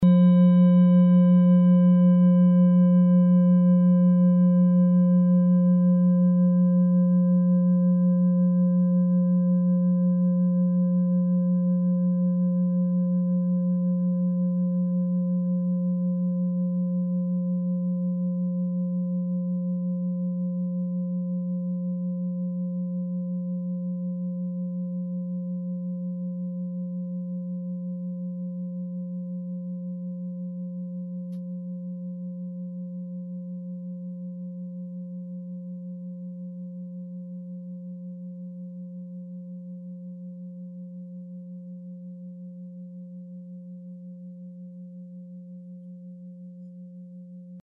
Klangschale Bengalen Nr.36
Klangschale-Durchmesser: 18,4cm
Sie ist neu und wurde gezielt nach altem 7-Metalle-Rezept in Handarbeit gezogen und gehämmert.
Die Frequenz des Jupiters liegt bei 183,58 Hz und dessen tieferen und höheren Oktaven. In unserer Tonleiter ist das in der Nähe vom "Fis".
klangschale-ladakh-36.mp3